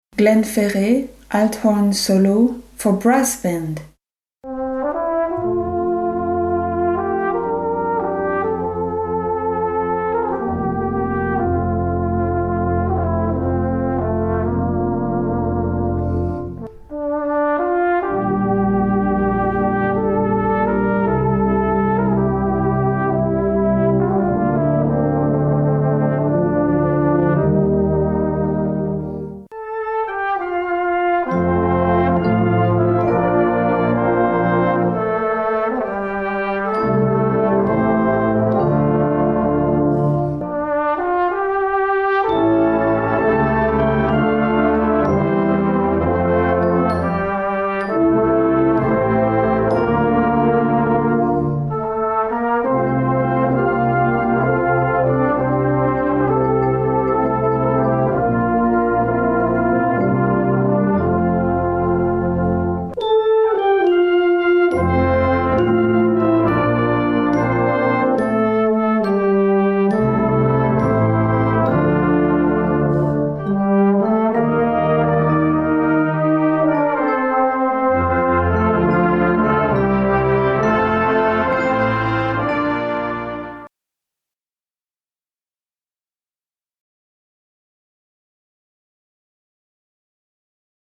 Brass Band
Celtic & Irish
Solo & Brass Band